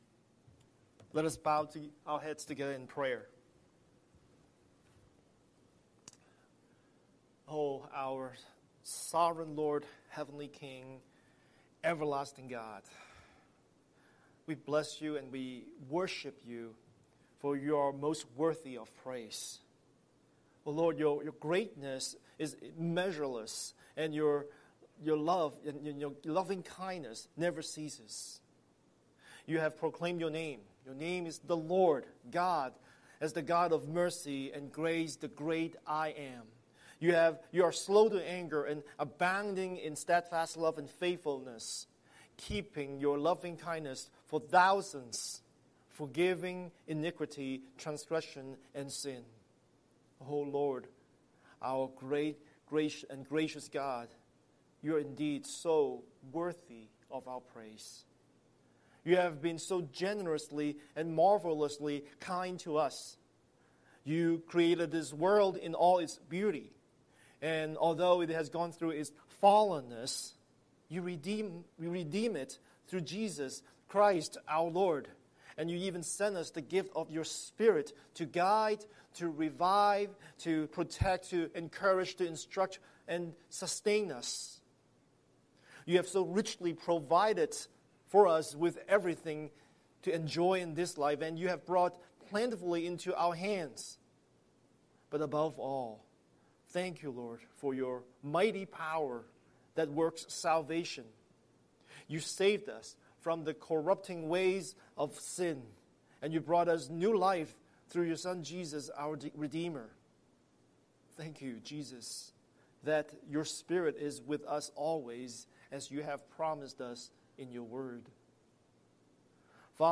Scripture: Galatians 5:2-12 Series: Sunday Sermon